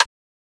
rim1.wav